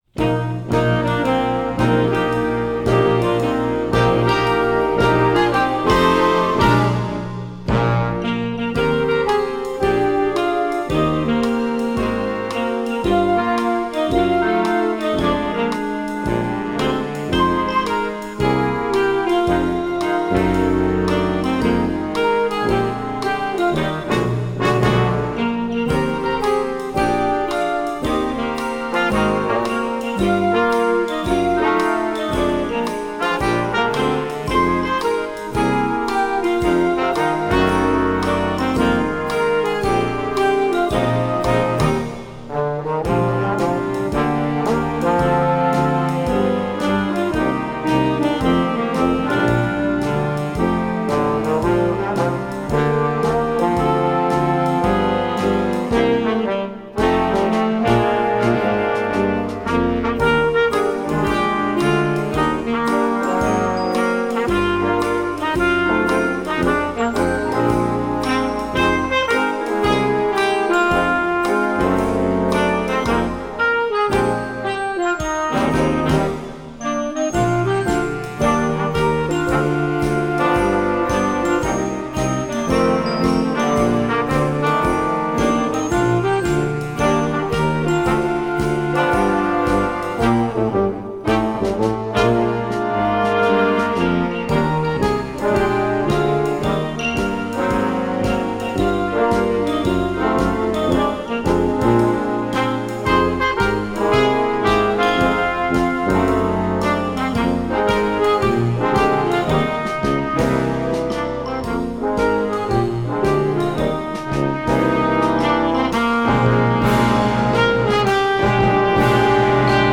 Gattung: Weihnachtsmusik für Jugendblasorchester
Besetzung: Blasorchester